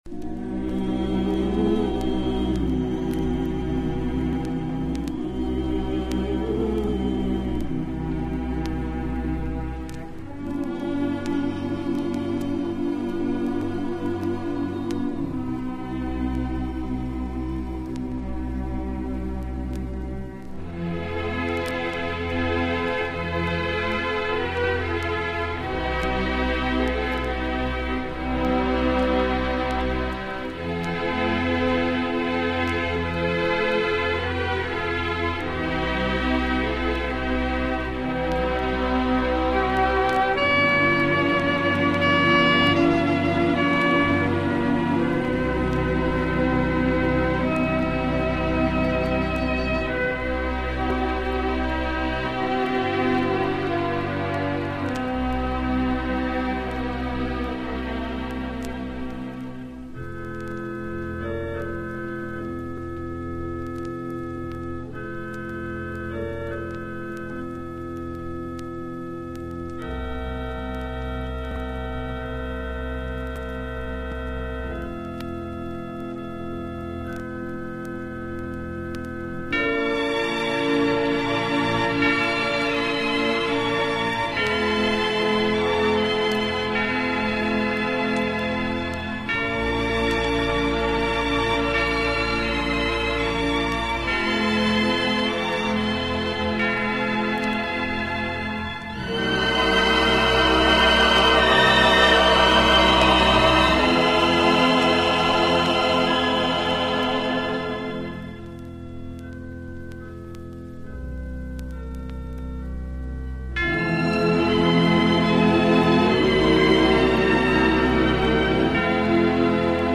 Genres: Christmas/Holidays